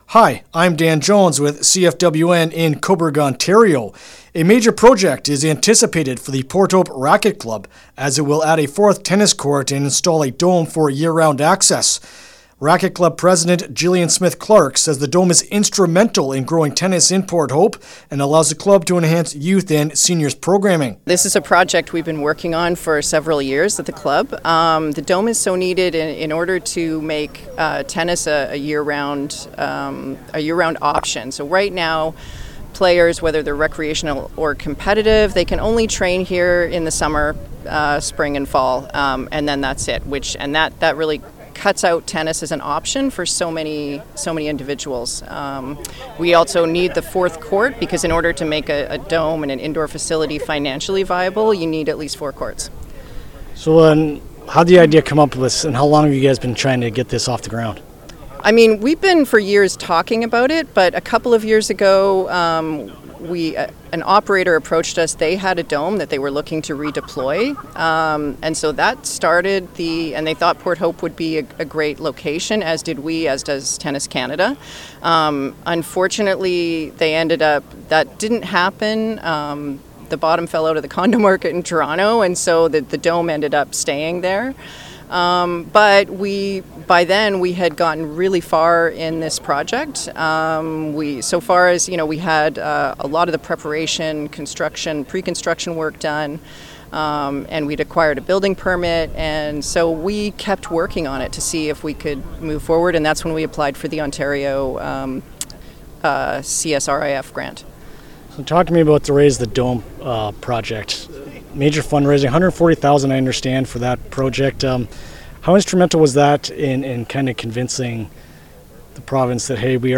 Port-Hope-Racquet-Club-Interview-LJI.mp3